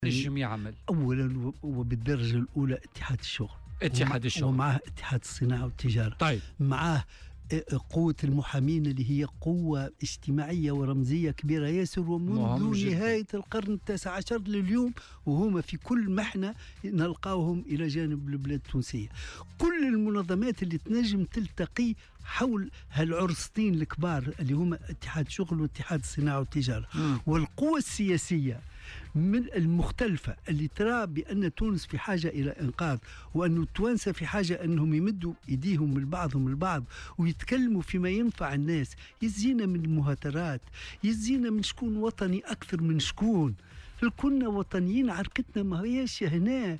وأضاف في مداخلة له اليوم في برنامج "بوليتيكا" أنه لابد للمنظمات الوطنية (اتحاد الشغل ومنظمة الأعراف) ومختلف القوى السياسية الأخرى أن تلعب دورها لإيجاد حلول لإخراج البلاد من مأزقها.